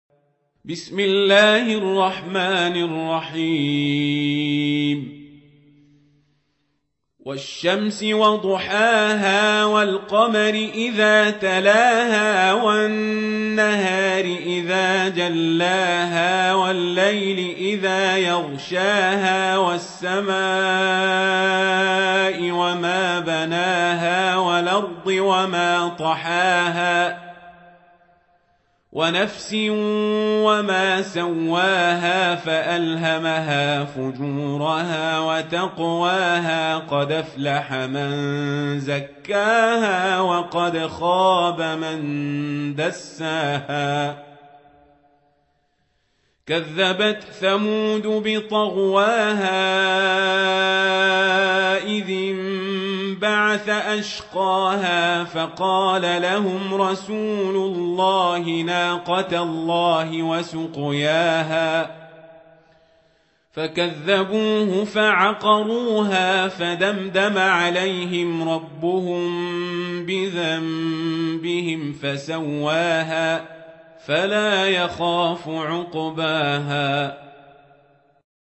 Récitation